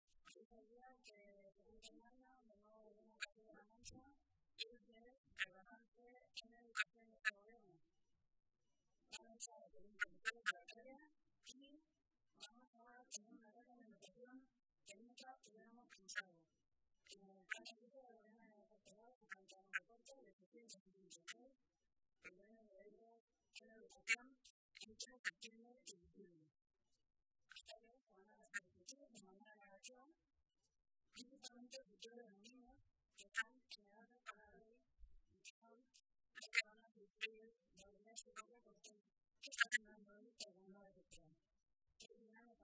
Mari Carmen Rodrigo, portavoz de Educación del Grupo Sacialista
Cortes de audio de la rueda de prensa